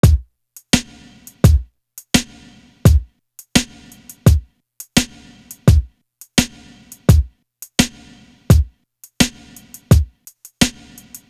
Legacy Drum.wav